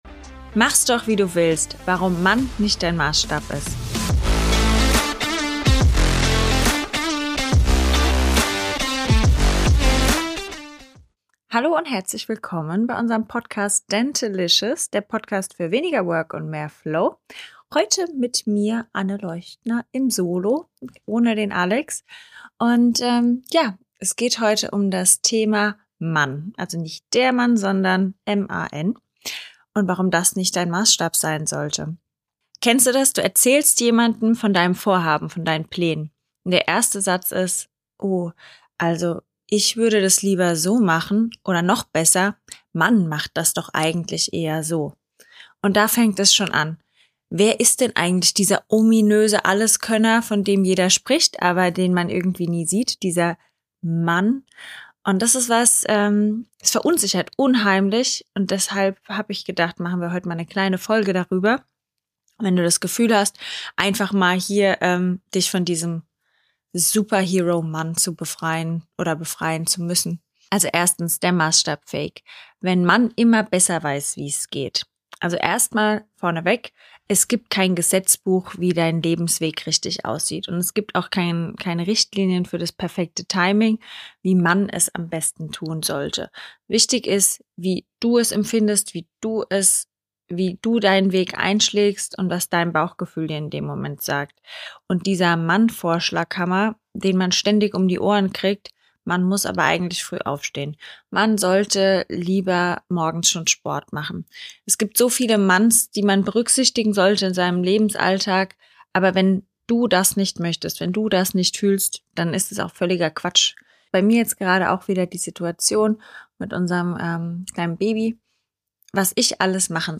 ehrlichen und augenzwinkernden Solo-Folge von Dentalicious spricht